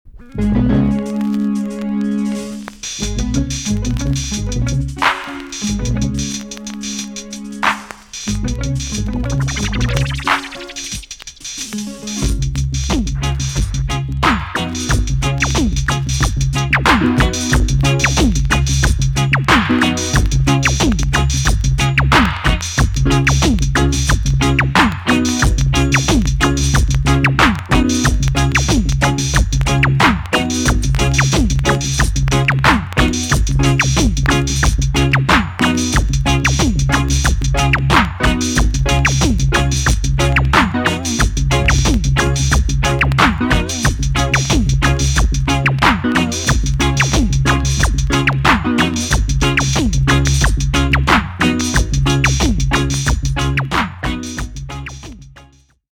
B.SIDE Version
VG+ 少し軽いチリノイズがあります。